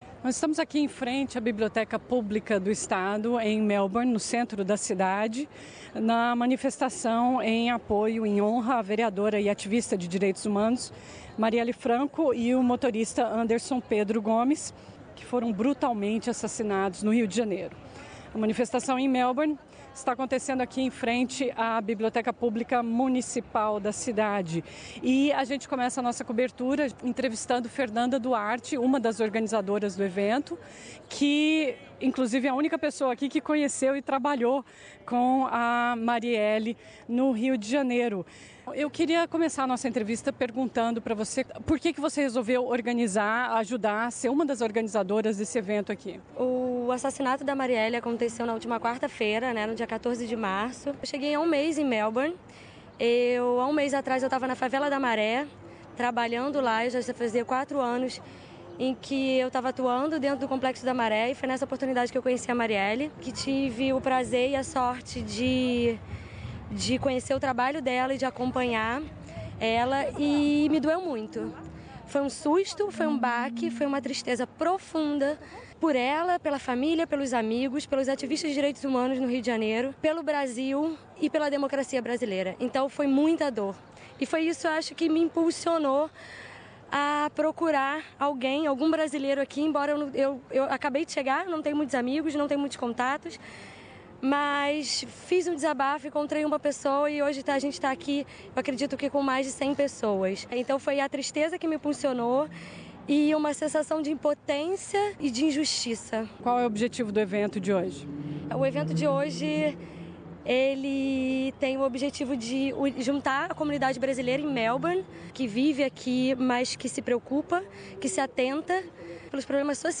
Ouça também a opinião de diversos participantes do evento em Melbourne.